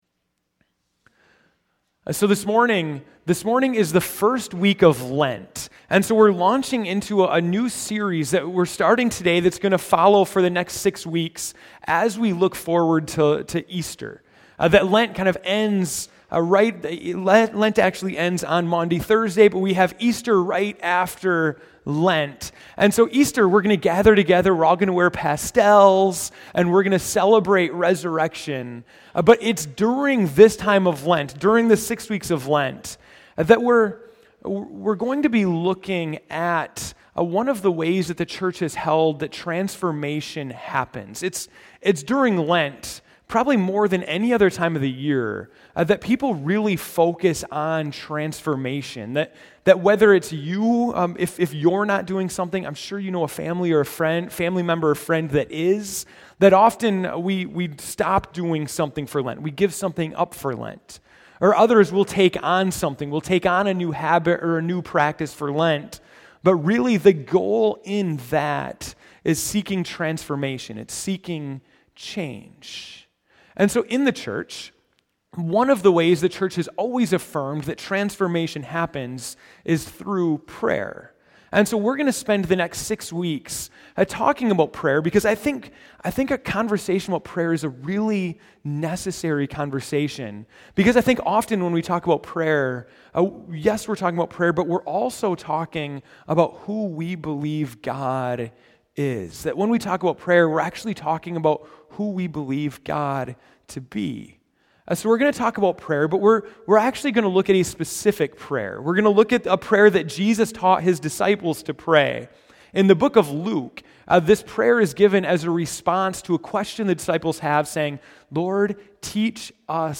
February 22, 2015 (Morning Worship)